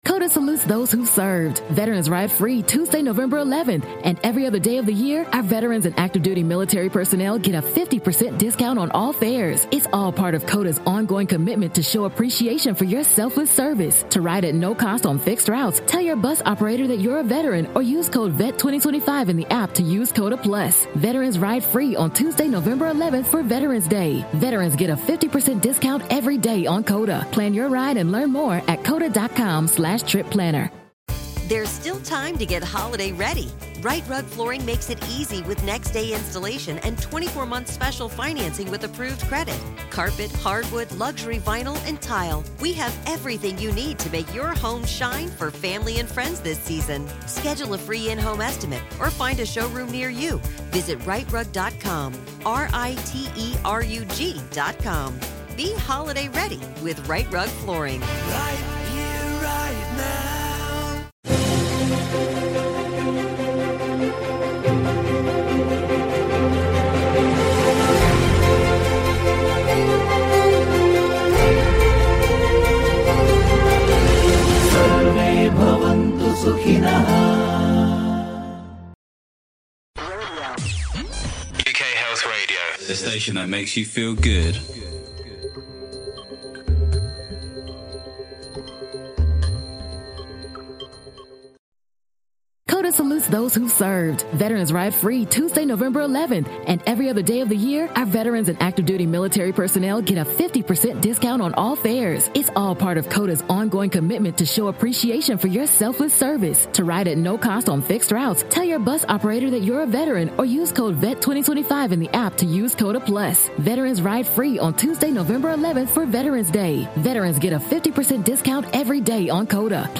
A thought-provoking interview with a cutting edge medical researcher and cardiologist